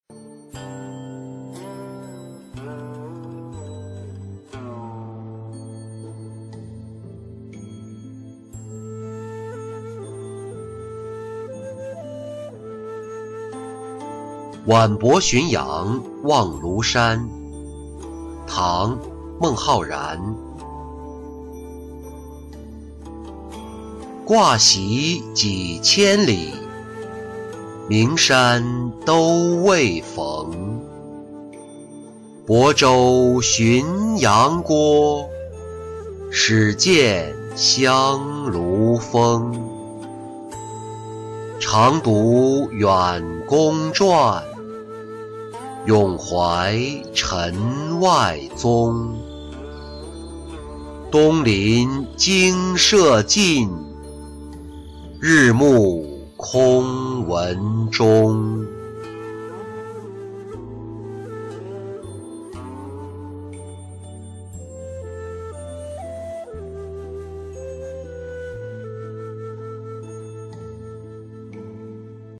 晚泊浔阳望庐山-音频朗读